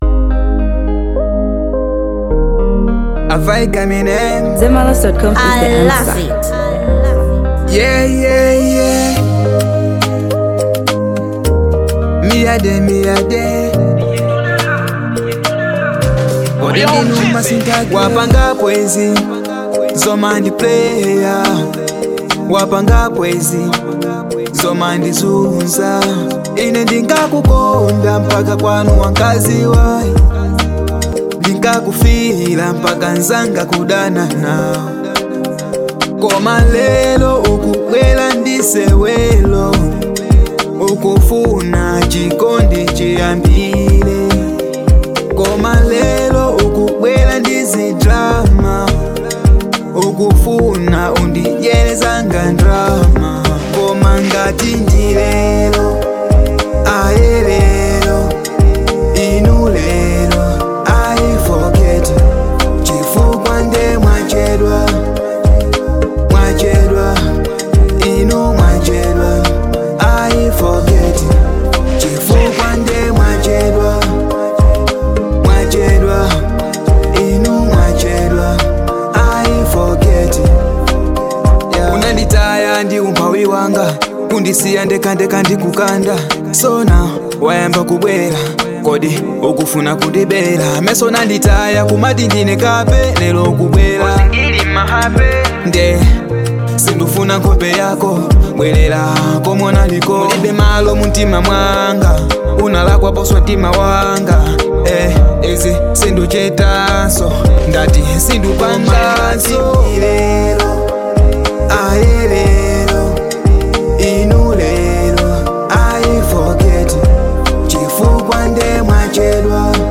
Afrobeats • 2025-09-05